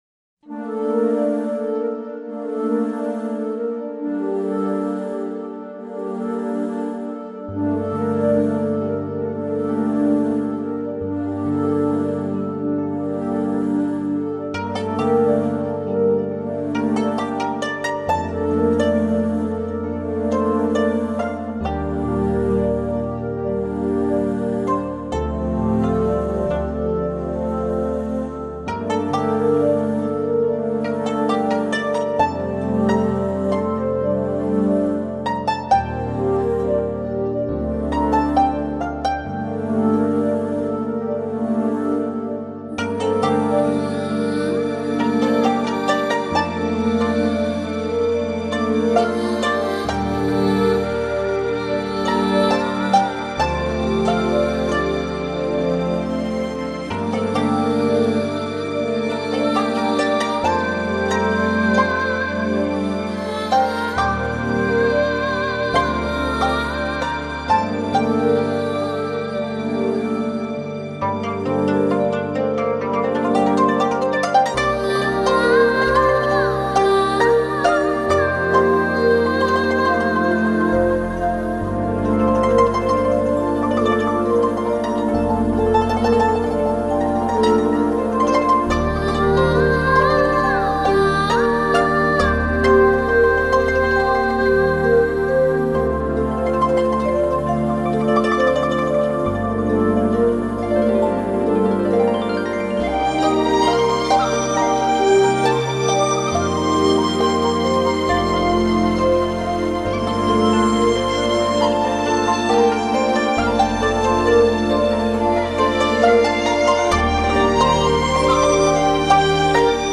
听着这音乐，总是会让我想到江南的雨，江南的幽巷，江南温婉纤雅的韵致。